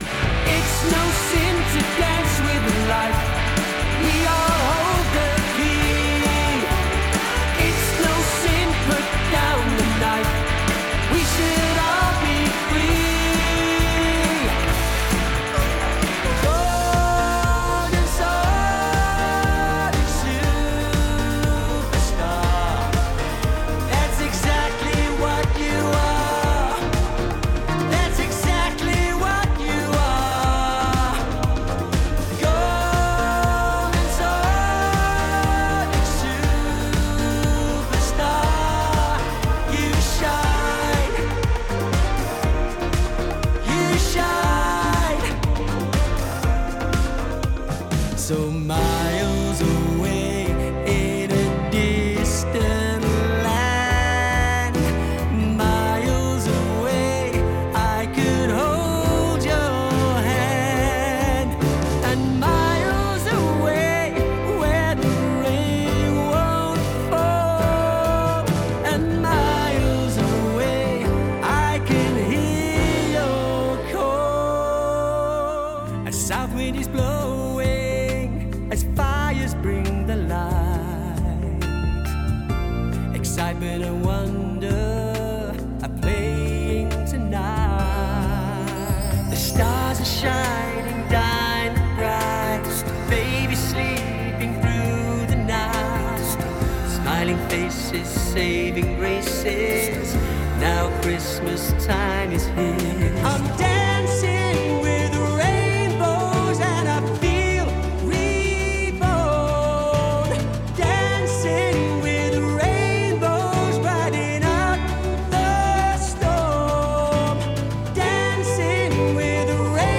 Commercial Showreel
Male
Manchester
Northern
Down To Earth
Gravitas
Husky (light)